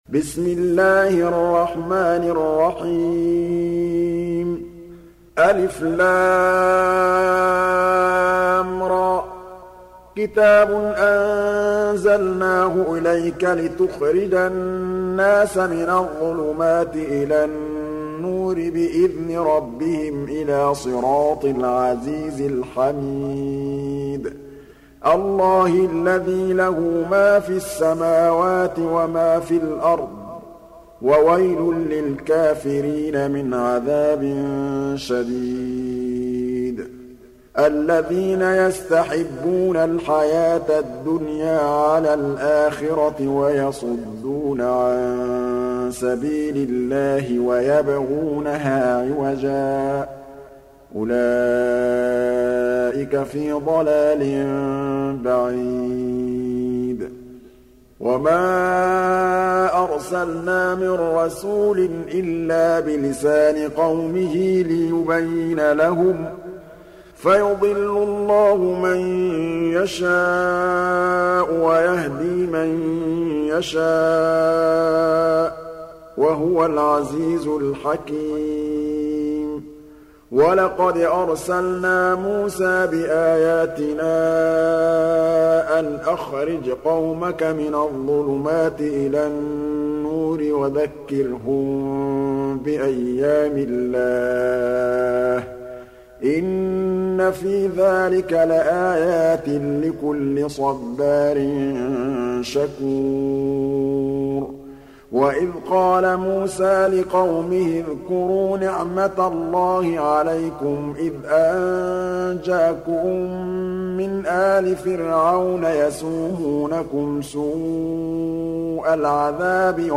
Surah Ibrah�m سورة إبراهيم Audio Quran Tarteel Recitation
Surah Repeating تكرار السورة Download Surah حمّل السورة Reciting Murattalah Audio for 14.